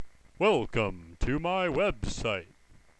When my cell phone gets low on battery power, it starts talking (that's the sound that you hear when you load up this page).